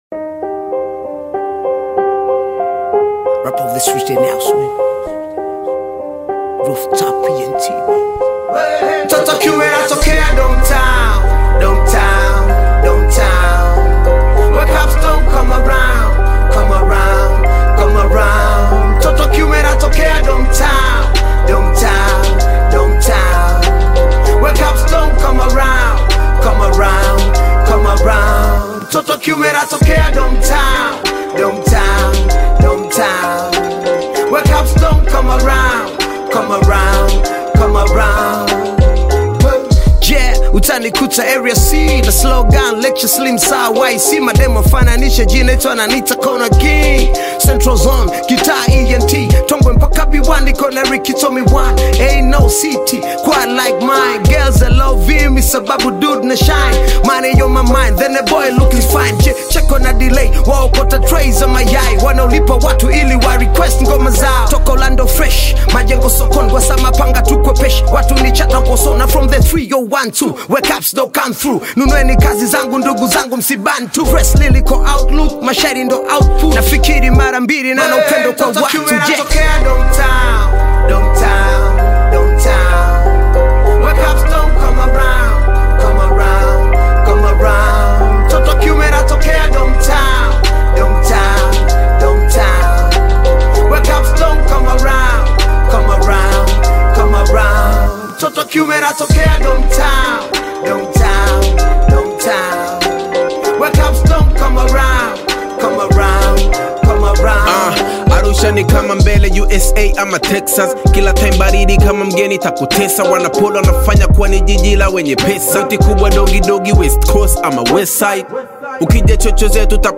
gritty Tanzanian Bongo Hip-Hop single
lyrical rapper
authentic East African hip-hop energy